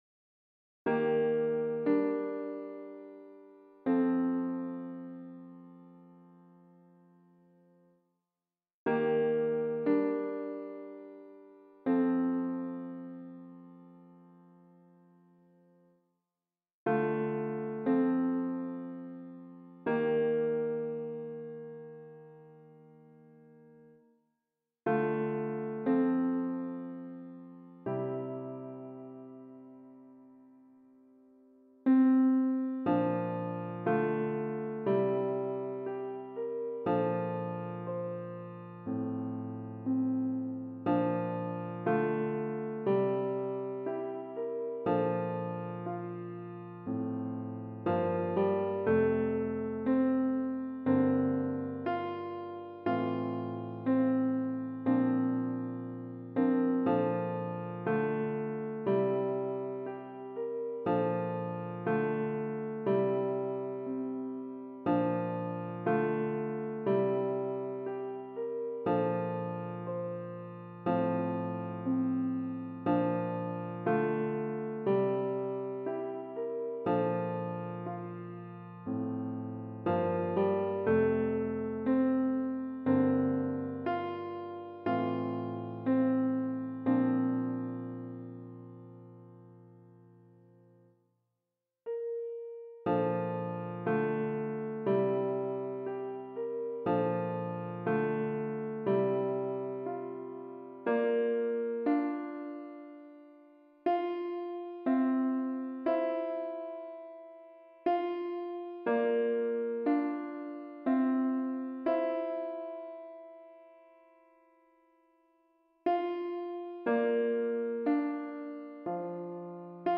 - Chant a capella à 4 voix mixtes SATB
Tenor Piano